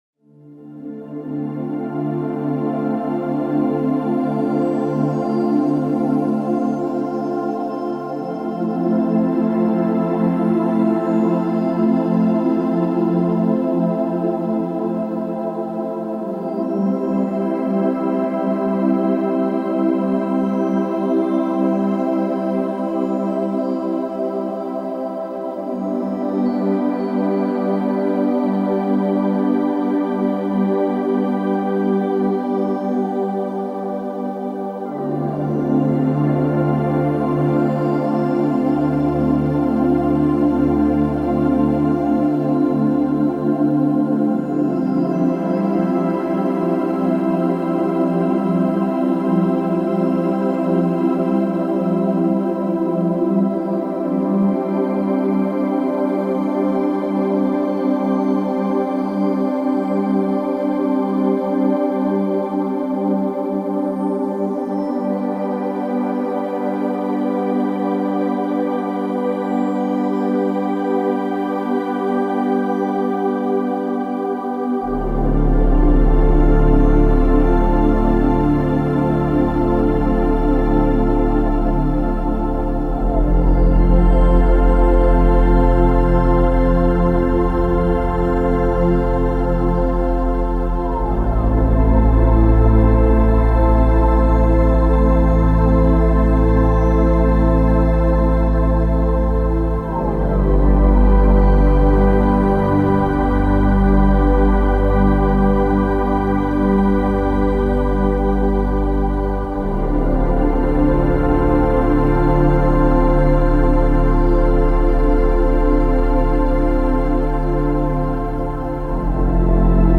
Ambient-Space-Music.-Space-Journey-Romantic-Deep-Relaxation-Stress-Relief-Dreaming.mp3